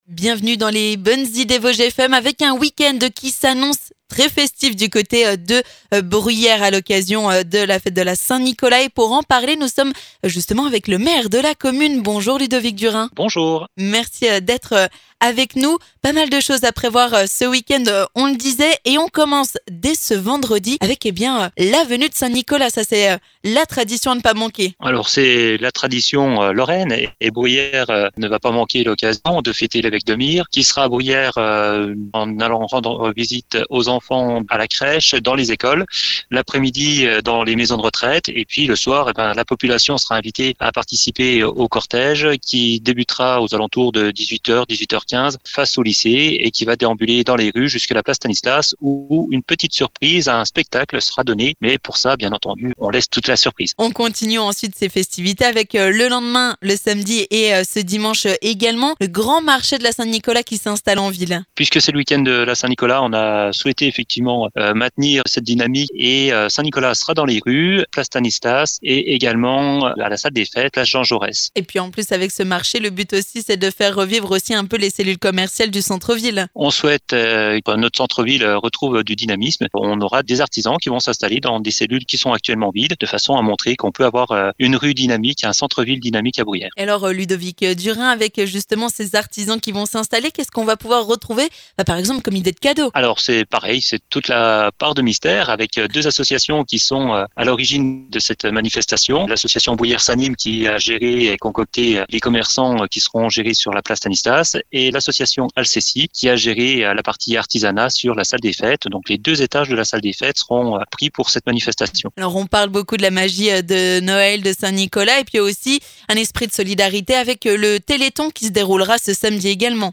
Nous sommes allés à la rencontre de Ludovic Durain, maire de Bruyères.